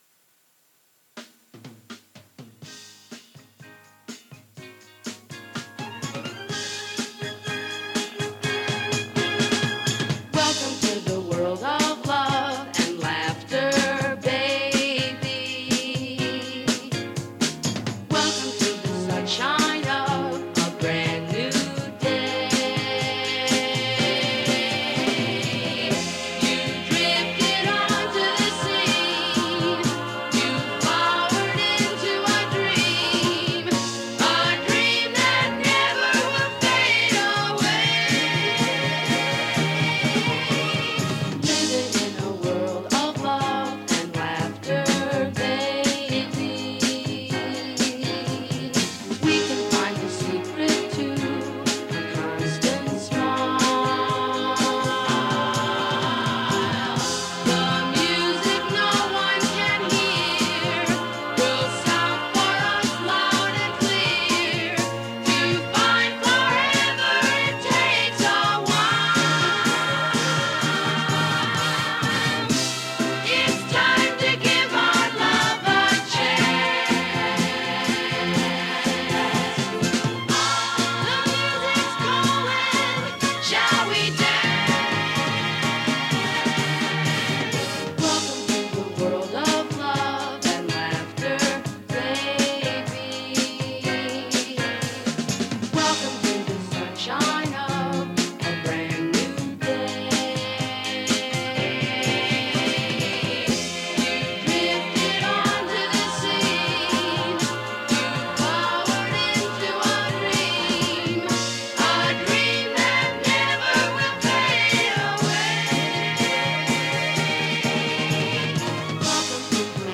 We had yet another audio problem in the form of me forgetting to turn the mic on… so what you’ll hear for my speaking bits are rerecorded with an admittedly less better mic.